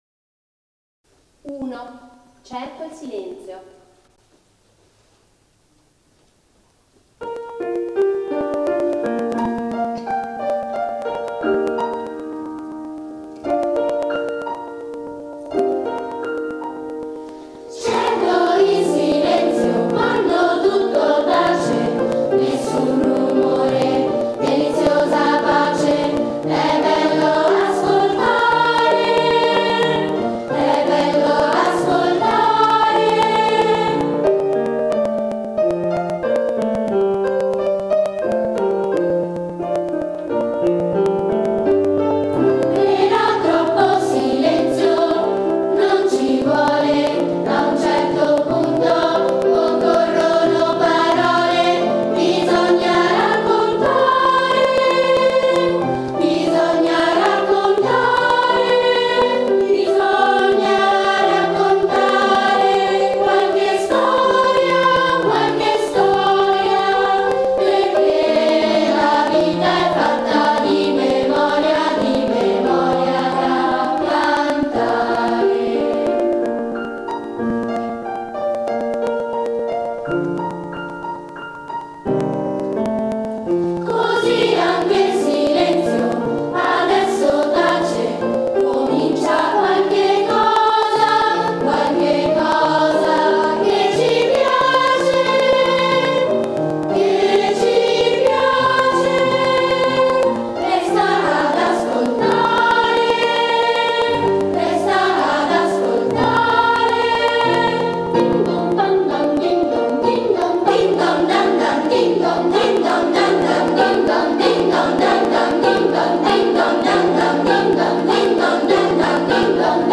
Audio dagli spettacoli e dai seminari in cui sono stati coinvolti gli allievi e gli insegnati dell'Accademia Musicale Giuseppe Verdi